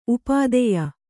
♪ upādeya